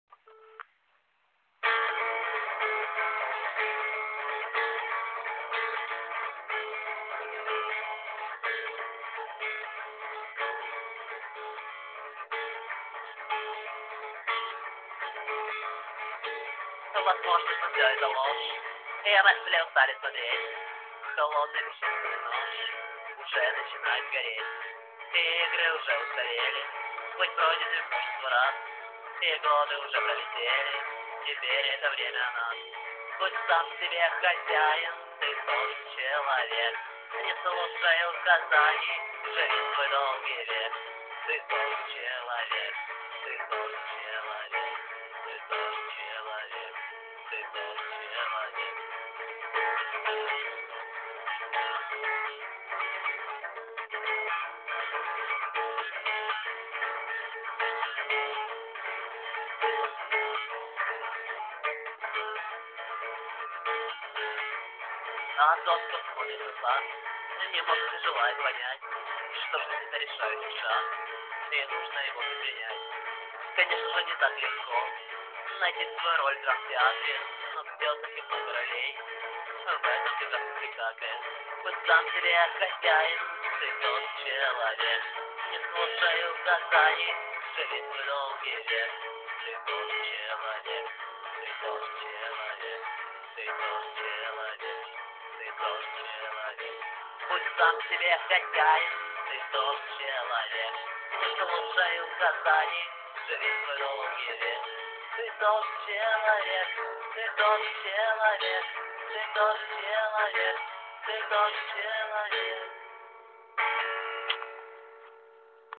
о, пісня! круто) жаль, що якість така... не дуже, а так все класно, слова супер
стих понравился, а запись х*****я.
Хотя я оч люблю бардовские песни, и эту запись тоже.